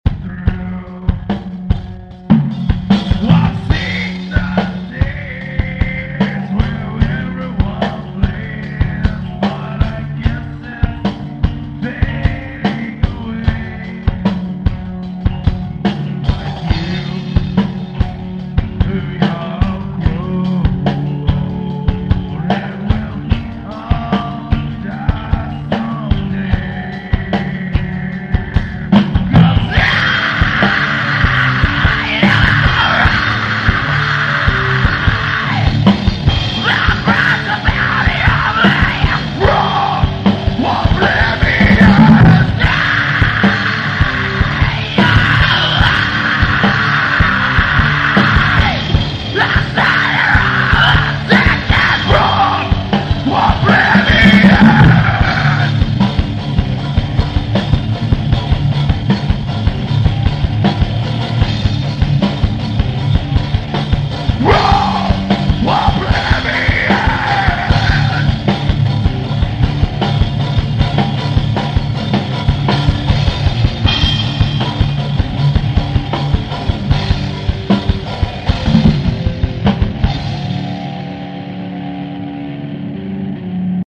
(just remember this is one is done on a home stereo.)